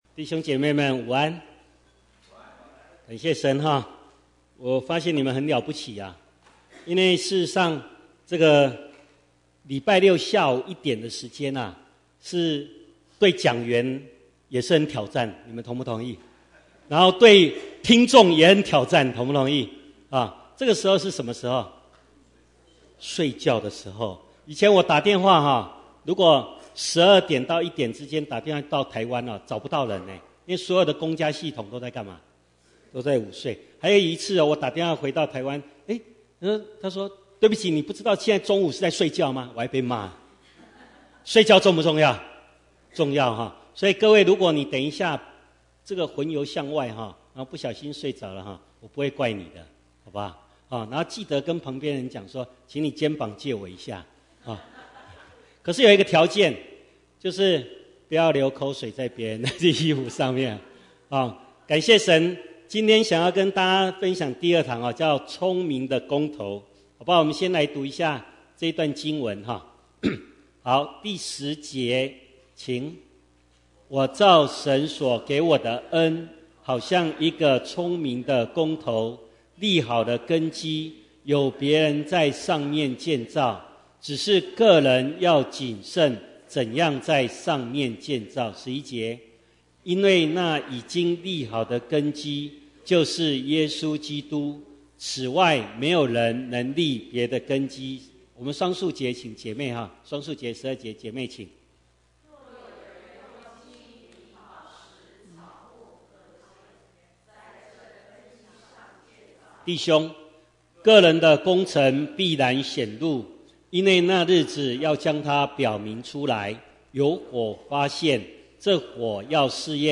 Bible Text: 歌林多前書 3 :10~15 | Preacher